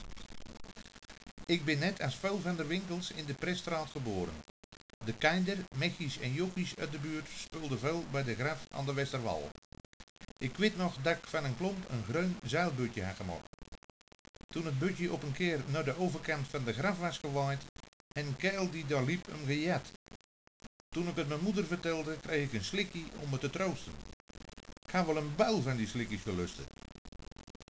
Een Kuilenburgs audio fragment